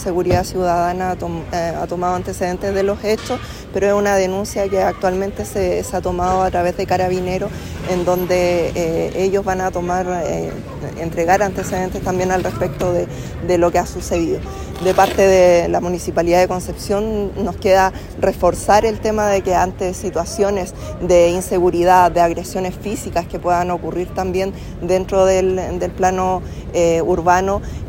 Desde el municipio confirmaron que el procedimiento lo adoptó carabineros y ellos han entregado los antecedentes. Así lo señaló la alcaldesa (s) Sintia Leiton.